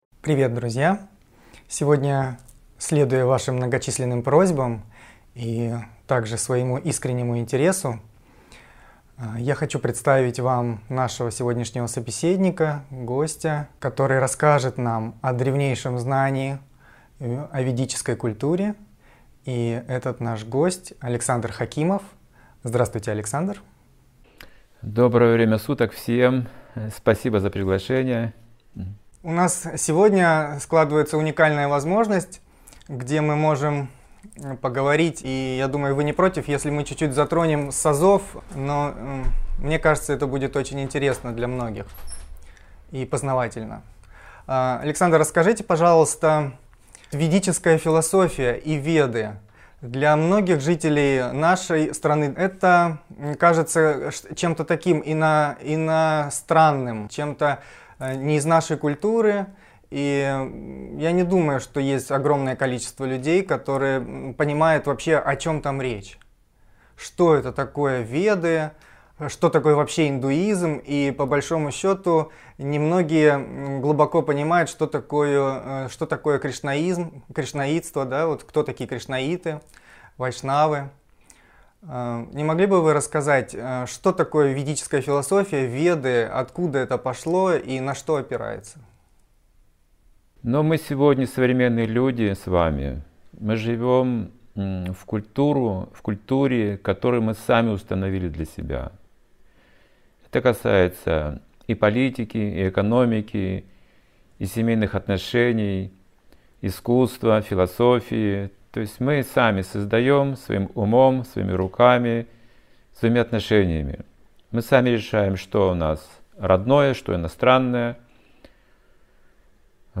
Алматы, Беседа